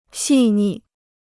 细腻 (xì nì) Free Chinese Dictionary